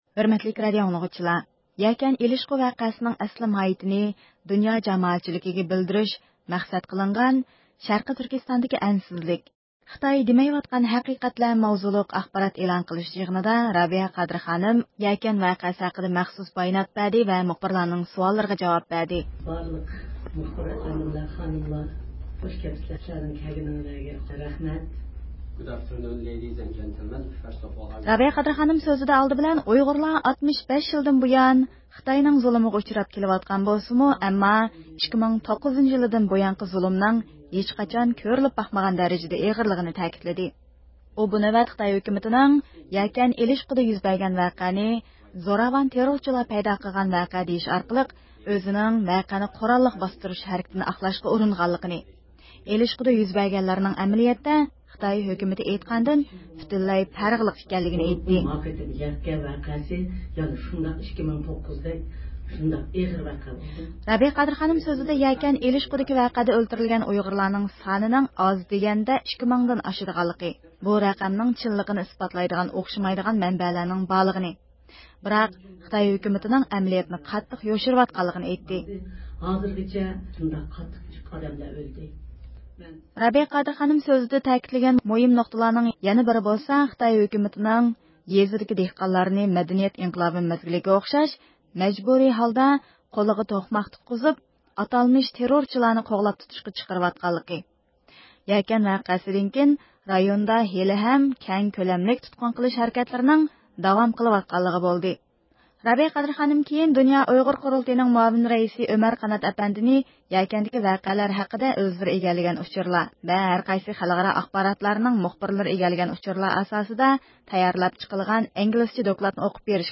چارشەنبە كۈنى، دۇنيا ئۇيغۇر قۇرۇلتىيى رەئىسى رابىيە قادىر خانىم ئامېرىكا دۆلەتلىك ئاخبارات كۇلۇبىدا يەكەن ۋەقەسىنىڭ تەپسىلاتى ھەققىدە ئېلان قىلغان باياناتىدا، خىتاي ھۆكۈمىتىنىڭ يەكەندە قىرغىنچىلىق قىلغانلىقىنى بىلدۈردى.
بۇ ئاخبارات ئېلان قىلىش يىغىنىغا ئامېرىكا، ياپونىيە، تۈركىيە ۋە تەيۋەن قاتارلىق دۆلەت ۋە رايونلارنىڭ ئاخبارات ئورگانلىرىنىڭ مۇخبىرلىرى قاتناشقان بولۇپ، زال ئىچى لىق تولغان ئىدى.